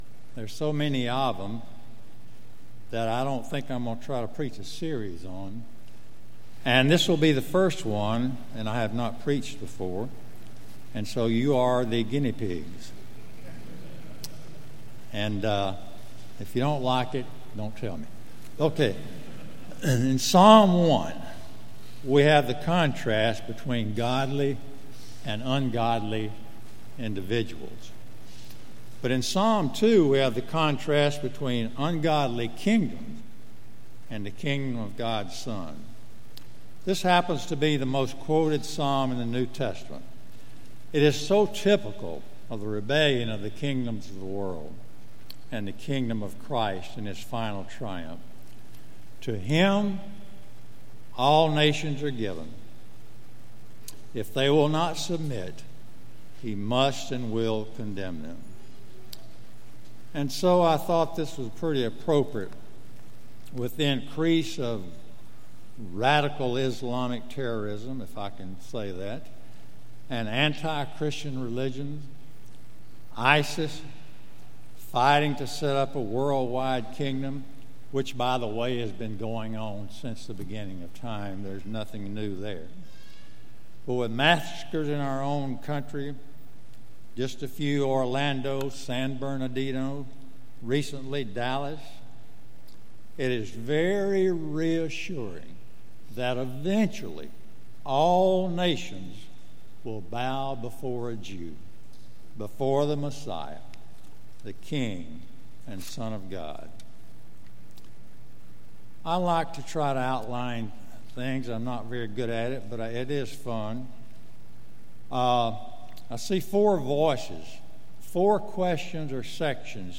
Sermon Audio from Sunday
Sermon on Psalm 2 from July 10